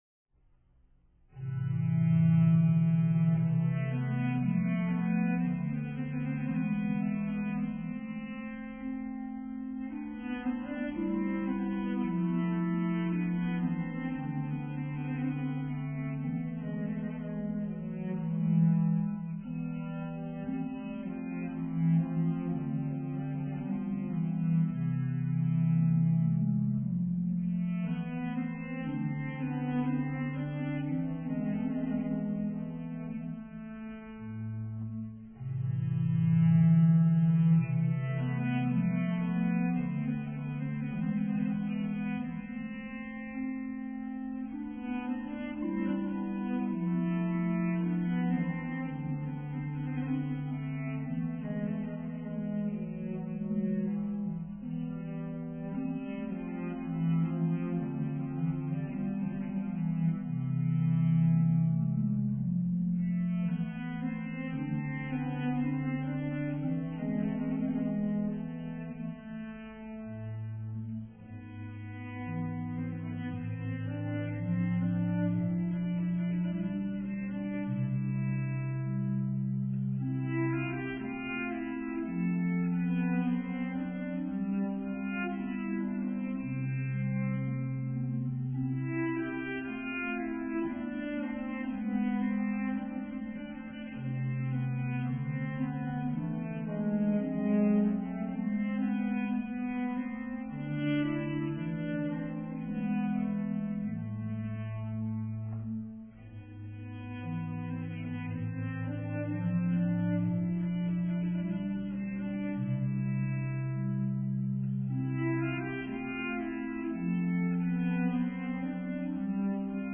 mp3 mono 8kbps